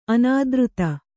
♪ anādřta